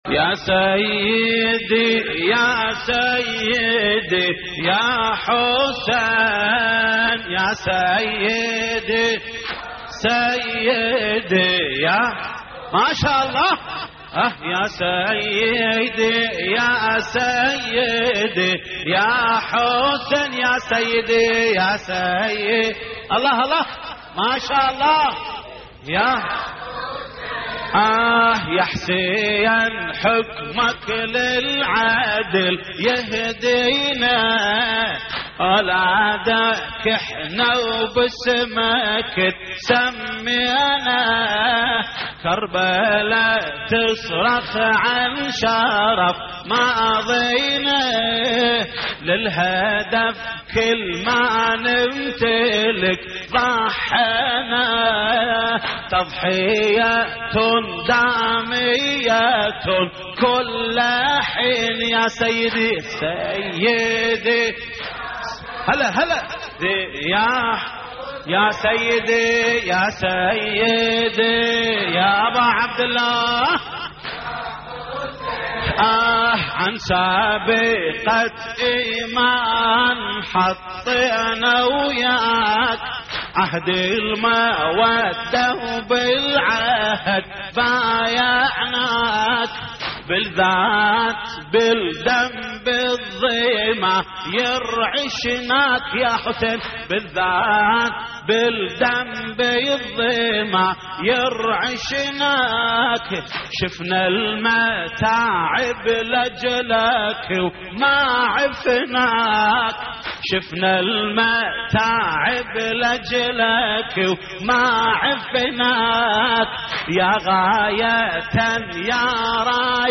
تحميل : يا سيدي يا سيدي يا حسين يا حسين حكمك للعدل يهدينا / الرادود جليل الكربلائي / اللطميات الحسينية / موقع يا حسين